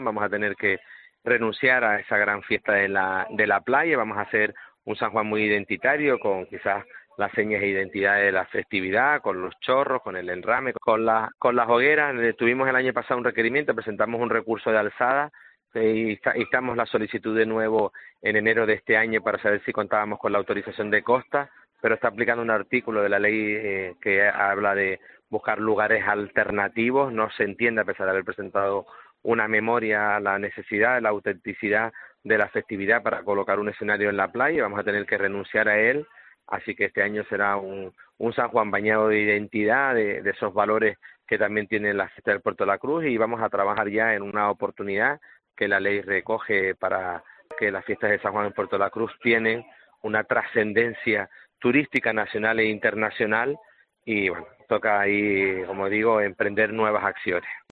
Marco González, alcalde de Puerto de la Cruz, habla sobre la prohibición de la fiesta de San Juan
El alcalde de Puerto de la Cruz, Marco González, ha confirmado en los micrófonos de COPE que este año la ciudad turística no podrá celebrar la Noche de San Juan en Playa Jardín como se ha venido haciendo tradicionalmente en el municipio.